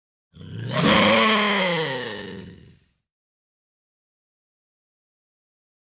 Рычание рыси звучит угрожающе